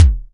Index of /90_sSampleCDs/EdgeSounds - Drum Mashines VOL-1/SIMMONSDRUMS